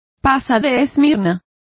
Complete with pronunciation of the translation of sultanas.